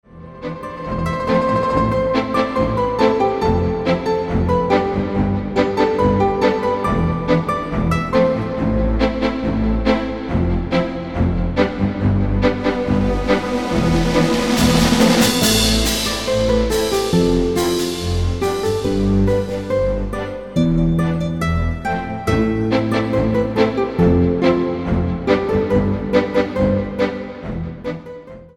Muzyka o charakterze muzyki filmowej.